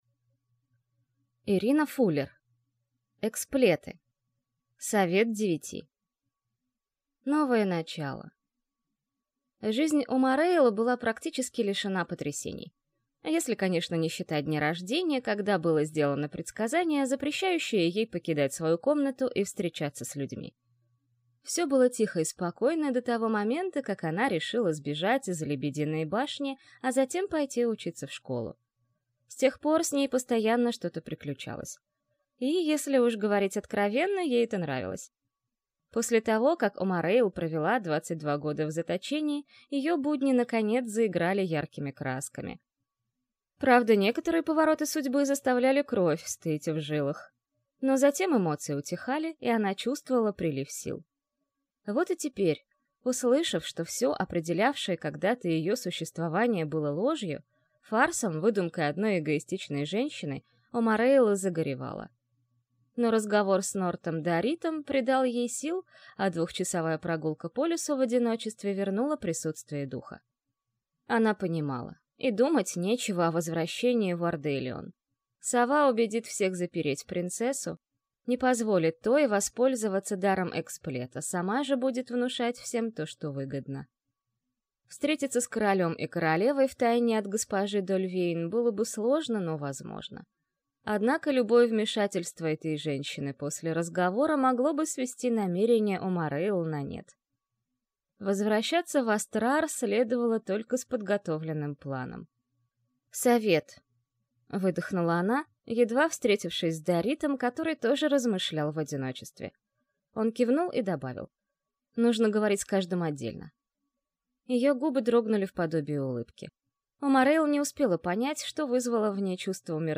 Аудиокнига Эксплеты. Совет Девяти | Библиотека аудиокниг